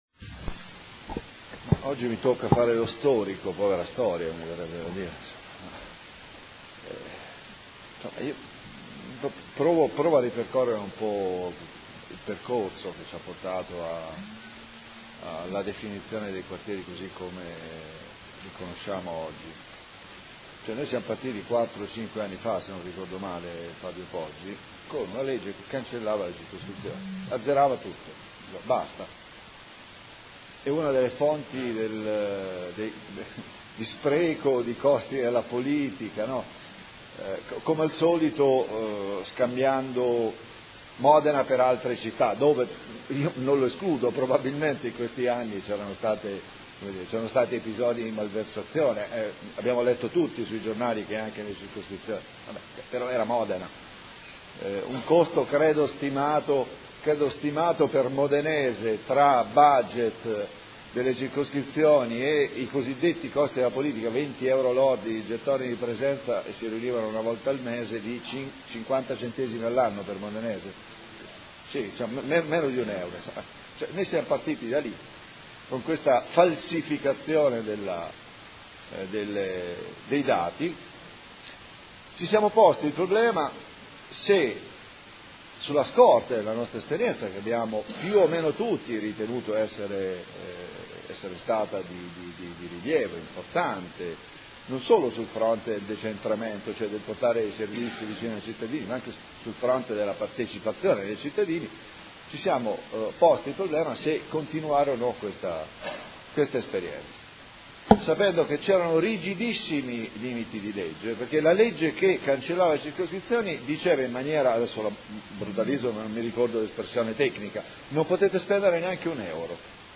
Seduta del 22 ottobre. Proposta di deliberazione: Consiglieri dimissionari dei Quartieri 1 e 3 – nomine nuovi componenti. Dichiarazioni di voto